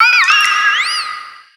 Cri de Manaphy dans Pokémon X et Y.